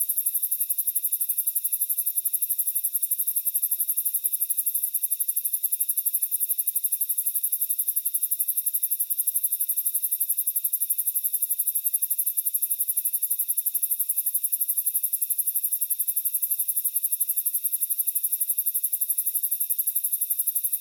insectnight_15.ogg